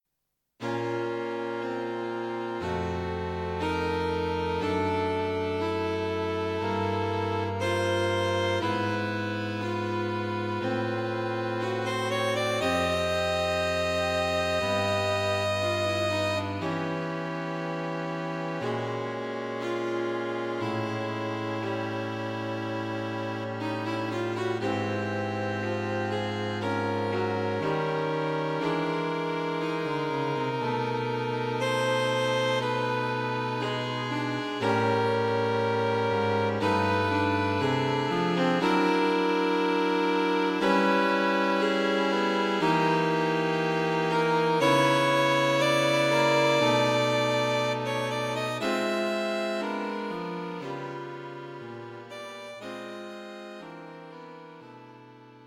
String Quartet
A modern, slow-moving piece.
Written for String Quartet.